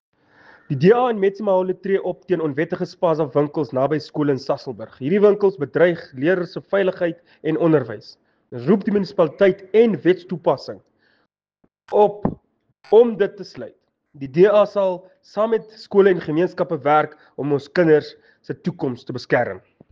Afrikaans soundbites by Cllr Phemelo Tabile and